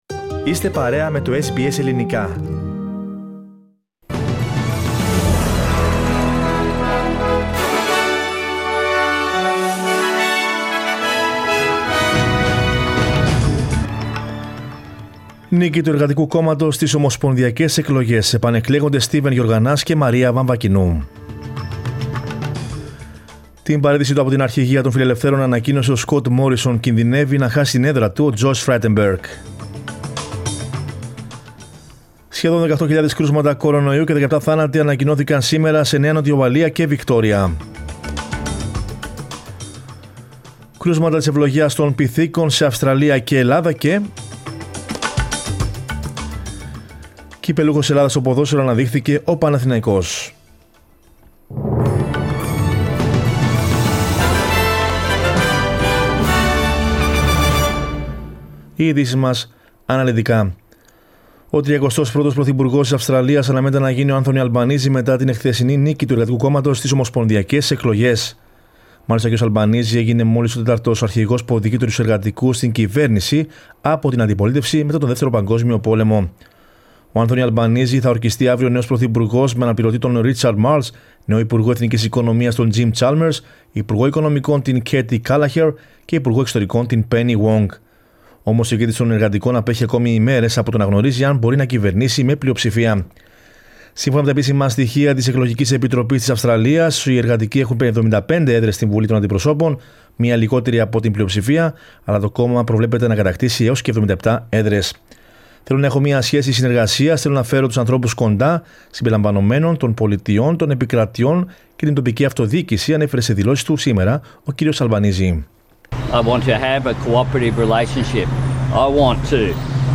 Δελτίο Ειδήσεων Κυριακής 22.05.22
News in Greek. Source: SBS Radio